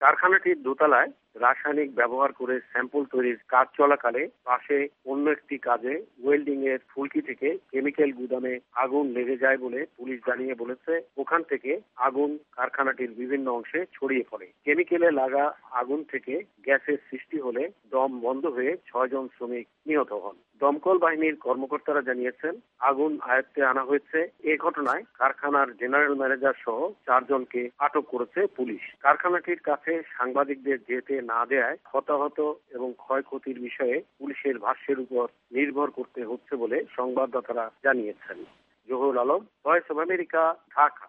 ঢাকা থেকে
রিপোর্ট।